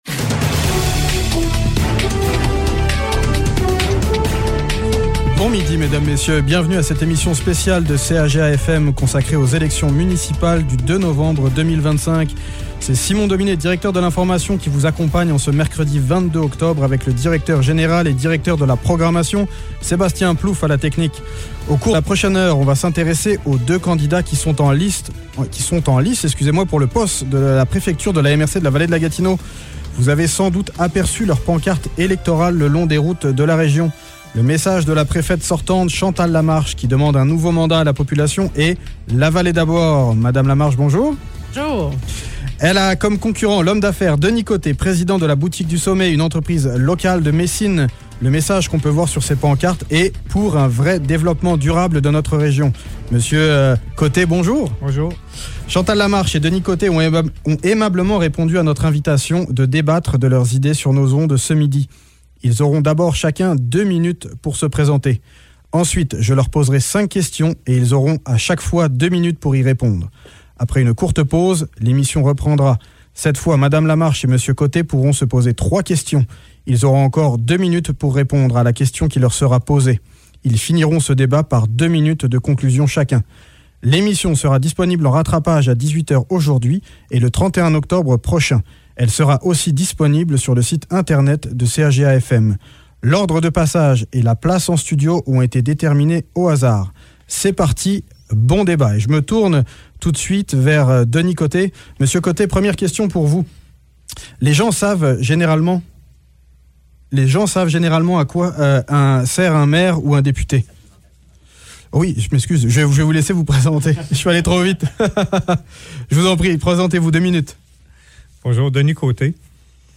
Débat de la préfecture